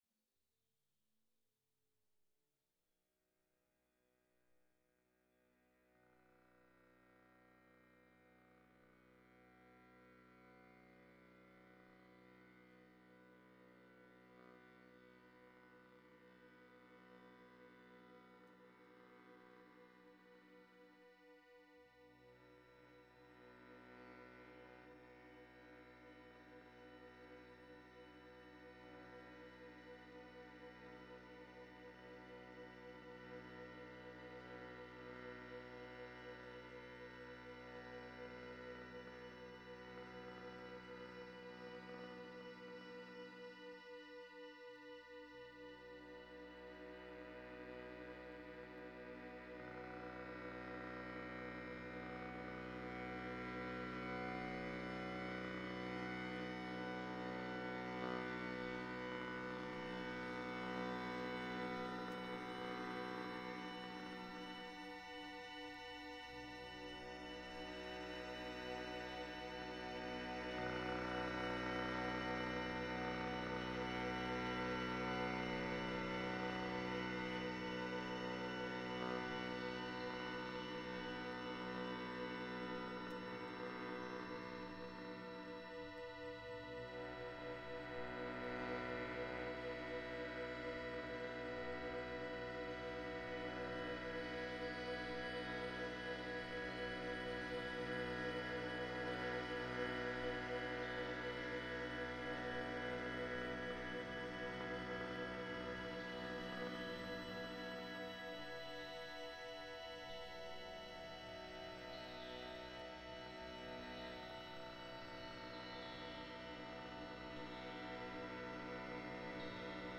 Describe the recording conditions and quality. Karnak Temple, Luxor reimagined